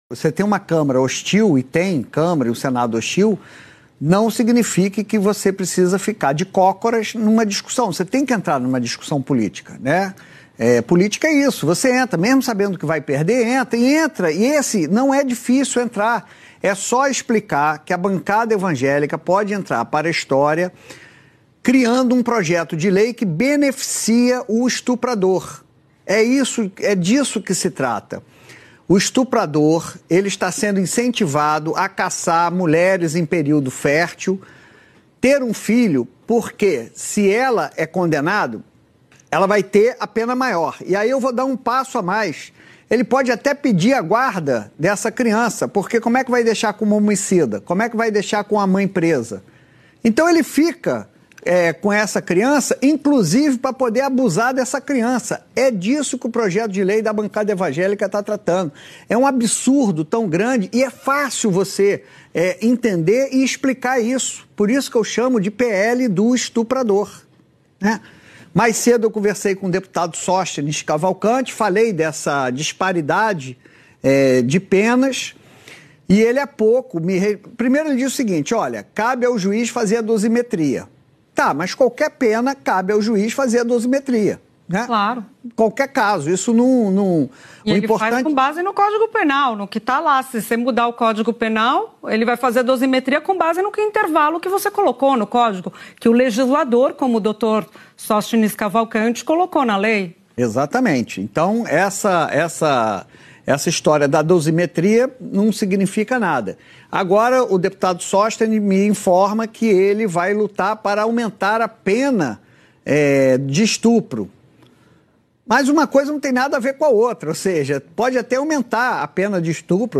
VEJA ABAIXO O COMENTÁRIO DO JORNALISTA OTÁVIO GUEDES/GLOBOPLAY